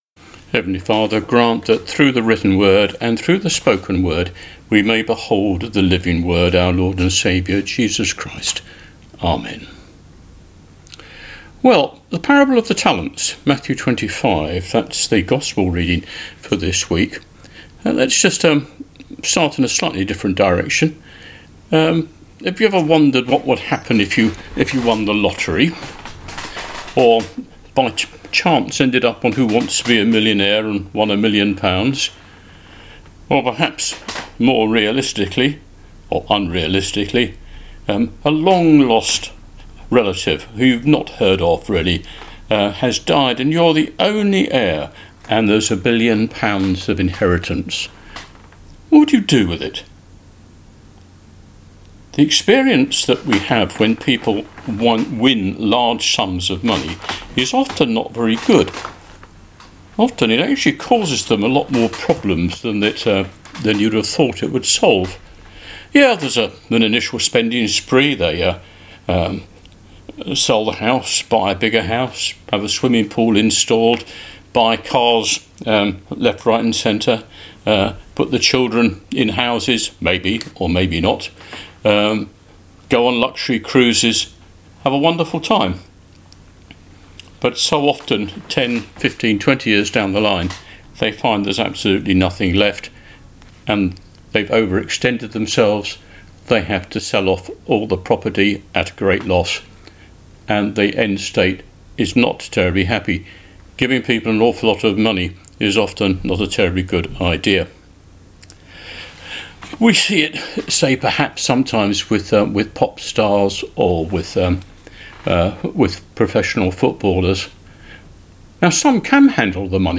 Sermon: Parble of the Talents | St Paul + St Stephen Gloucester